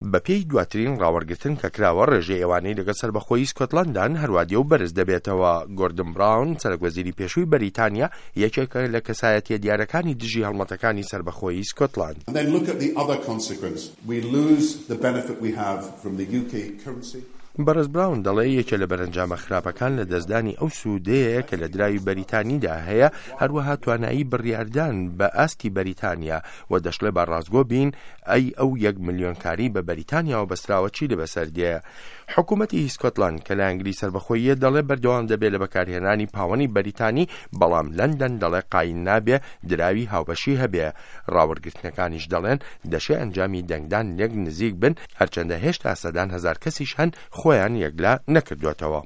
ڕاپـۆرتی سکۆتلاند